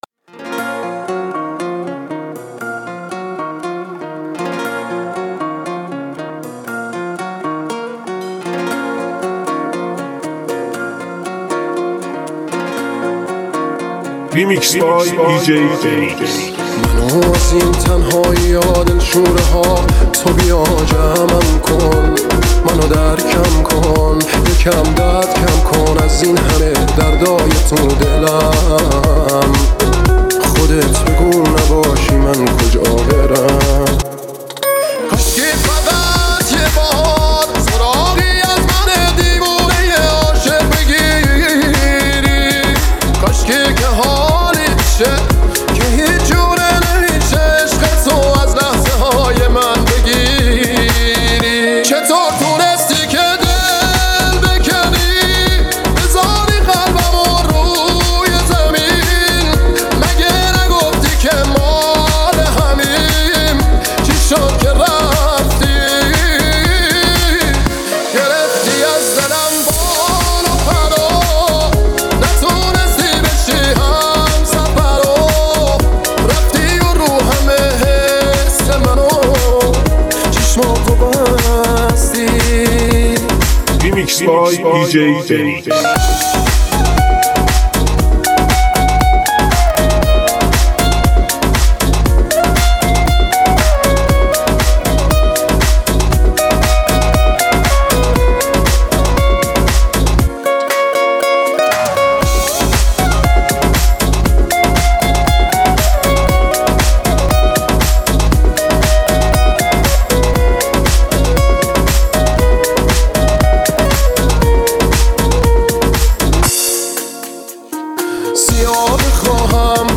آهنگ ریمیکس